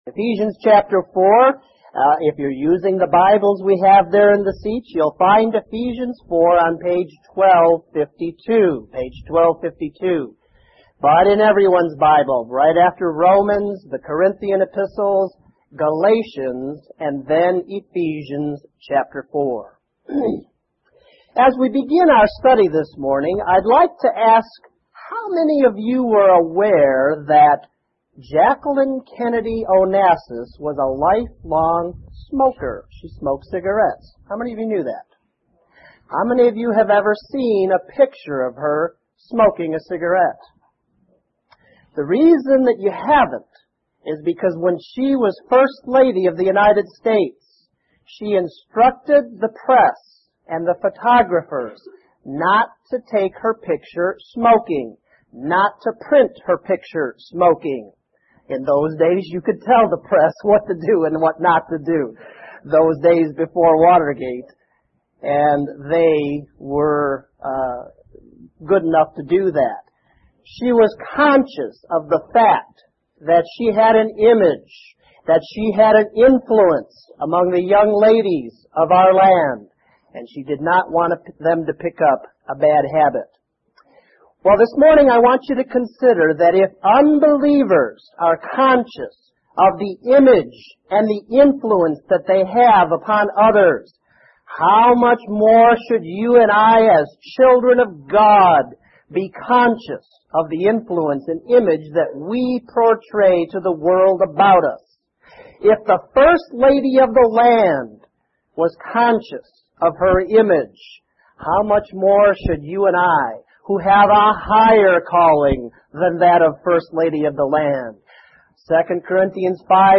Lesson 41: Ephesians 4:1-2